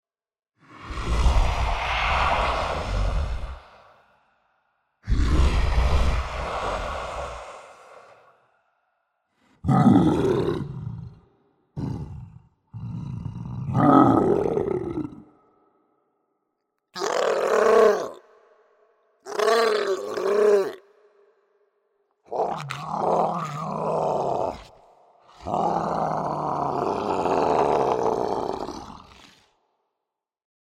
[角色类语音]
有低沉共鸣强烈的，有尖锐嘶哑的，各式各样的引导风格。